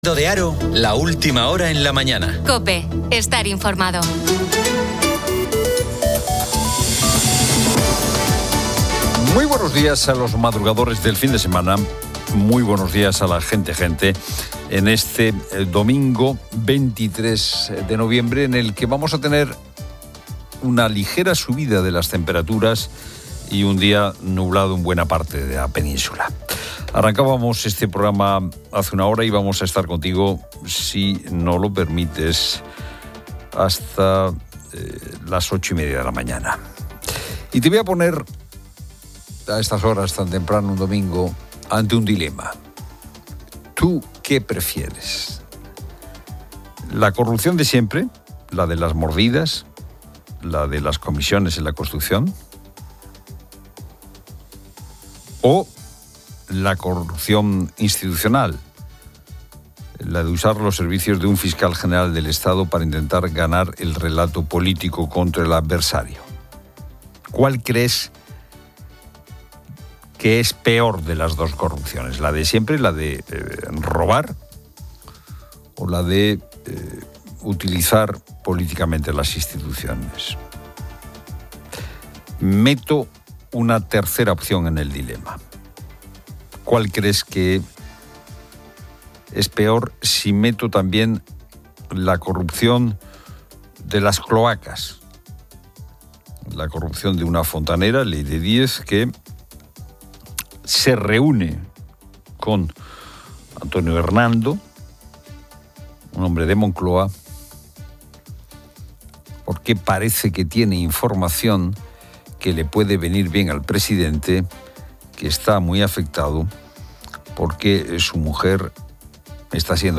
La radio informa sobre la controversia de la corrupción en España, destacando el caso de Santos Cerdán del PSOE, investigado por recibir comisiones,...